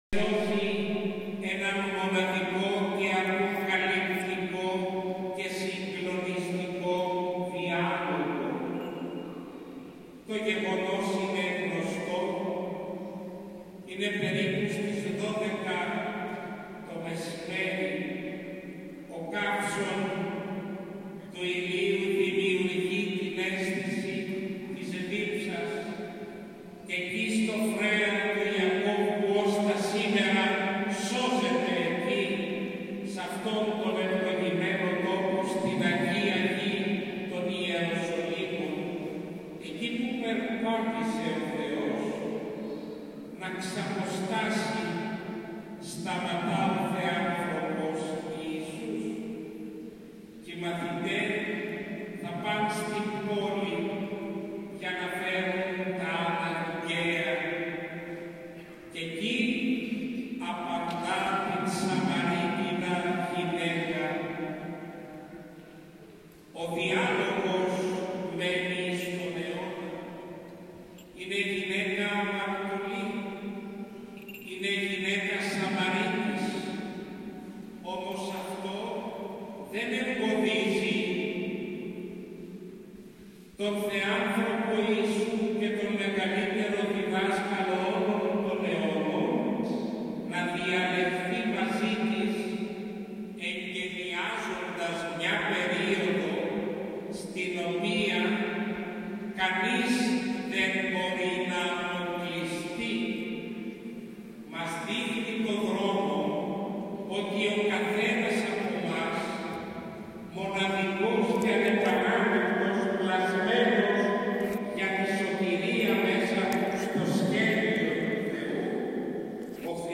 Αρχιερατική Θεία Λειτουργία και Χειροτονία στον Πανηγυρίζοντα Ι.Ν. Αγίας Φωτεινής Ευόσμου - Ορθοδοξία News Agency